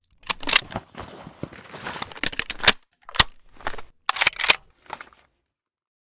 vityaz_reload_empty.ogg